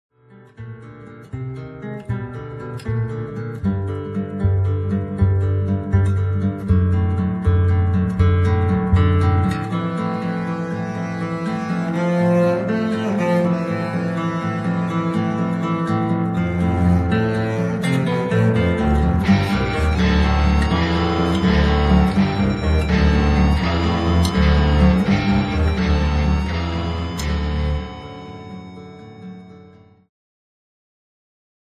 Segment Jazz-Rock
Progressive